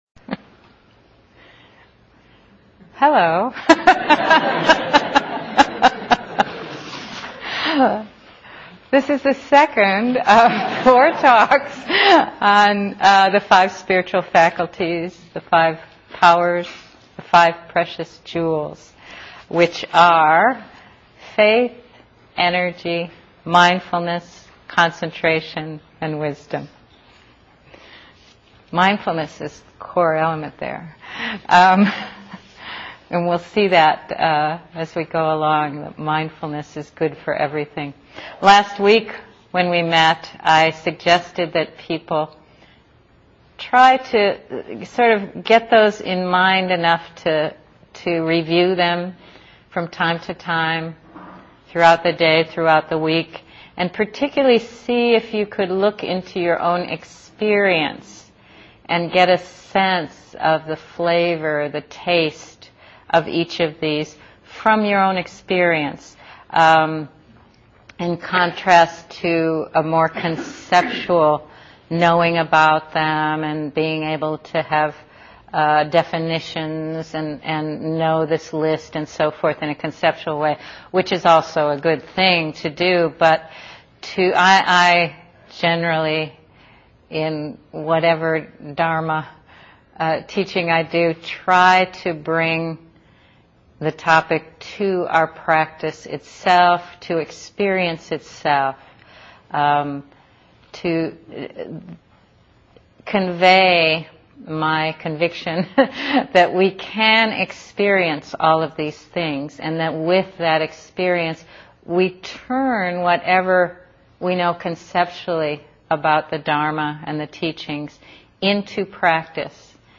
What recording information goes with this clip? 2008-03-04 Venue: Seattle Insight Meditation Center Series